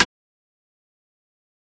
Snare (YOSEMITE) (1).wav